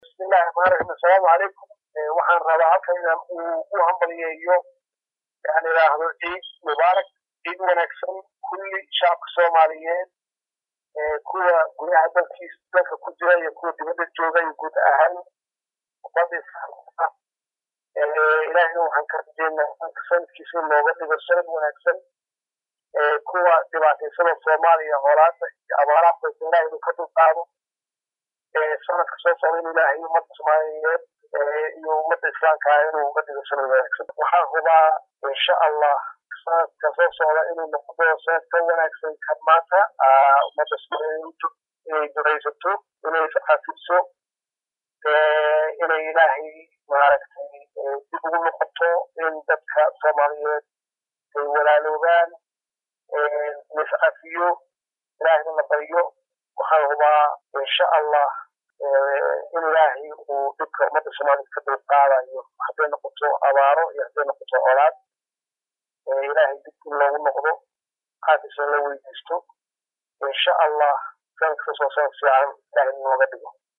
DHAGEYSO: CODKA R/WASAARIHII HORE EE SOOMAALIYA FARMAAJO;